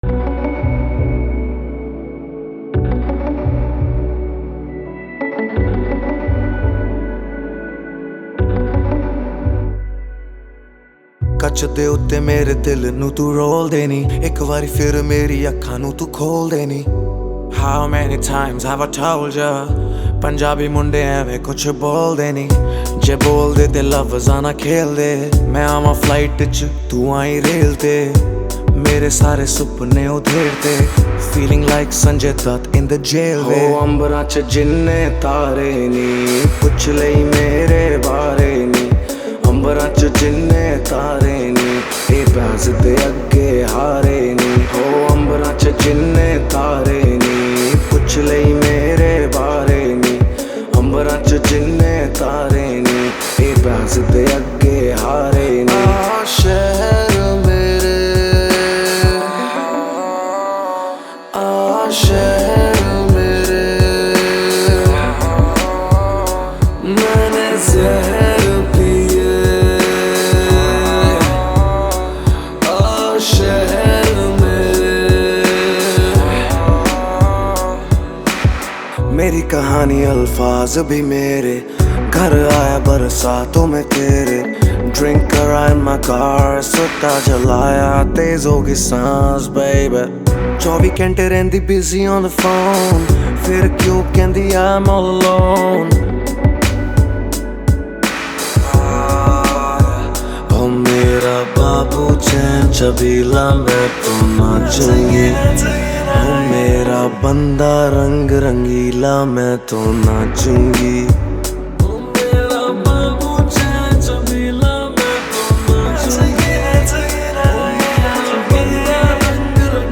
Category: Punjabi Single Songs